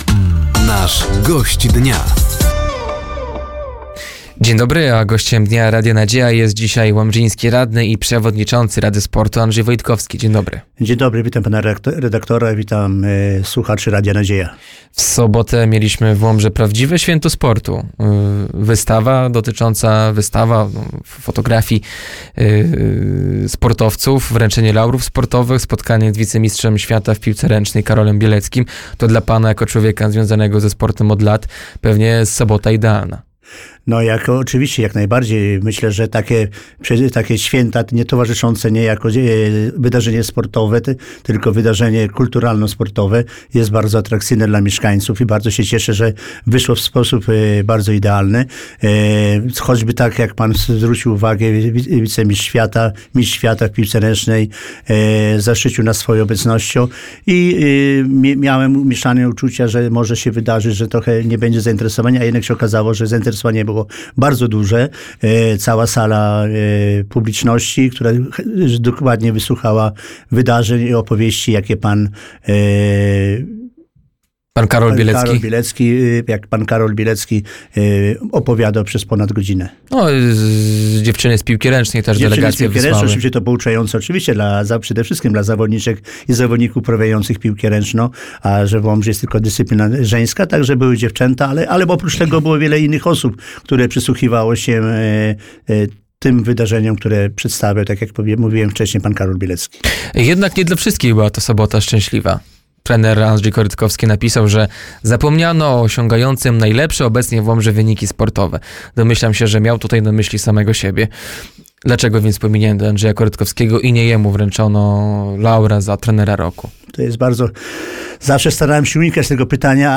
Gościem Dnia Radia Nadzieja był łomżyński radny i przewodniczący rady sportu, Andrzej Wojtkowski. Tematem rozmowy były Łomżyńskie Laury Sportowe, podział pieniędzy na sport oraz budowa nowego boiska przy Szkole Podstawowej nr 9.